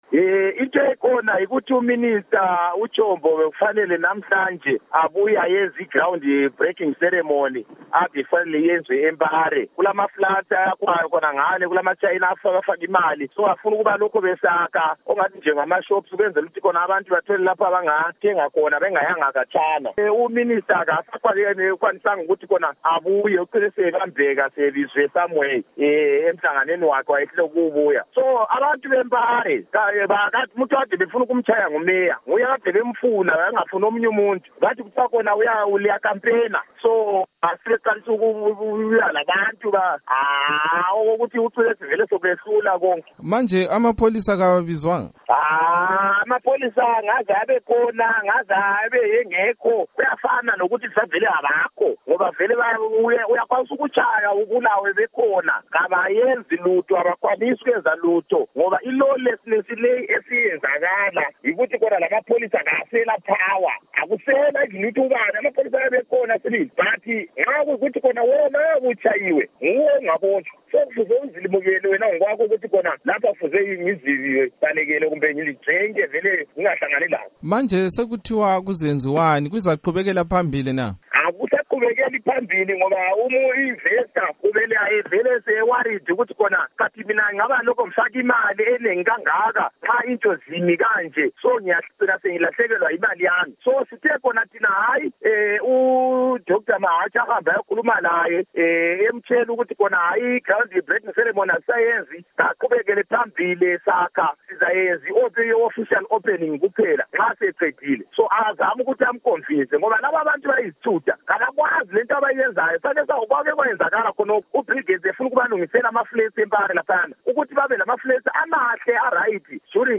Ingxoxo loKhansila Peter Moyo weHarare